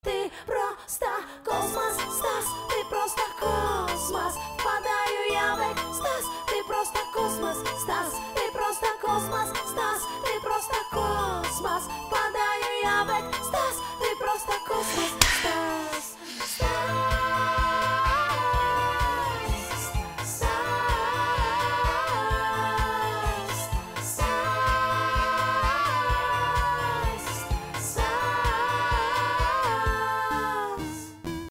• Качество: 320, Stereo
красивый женский вокал